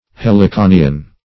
Search Result for " heliconian" : The Collaborative International Dictionary of English v.0.48: Heliconian \Hel`i*co"ni*an\, a. [L. Heliconius.] 1.